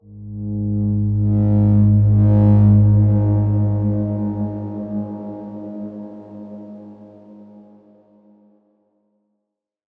X_Darkswarm-G#1-f.wav